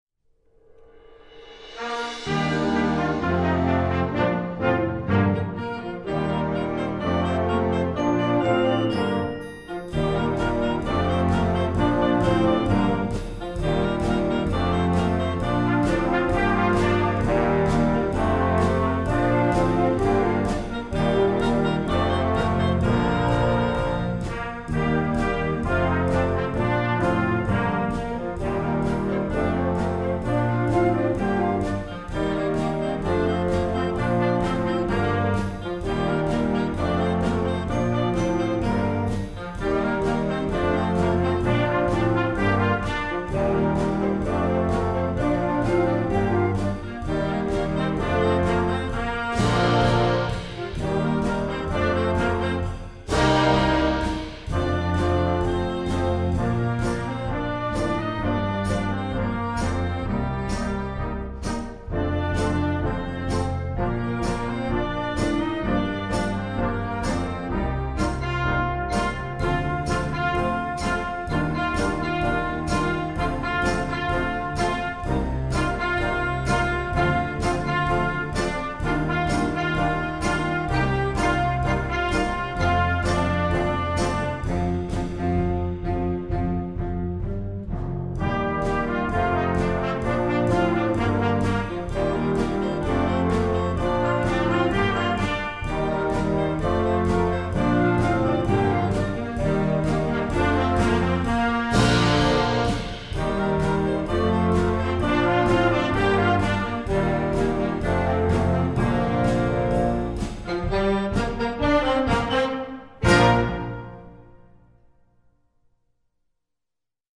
Gattung: Pop Beginning Band
Besetzung: Blasorchester